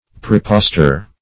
Prepostor \Pre*pos"tor\, n.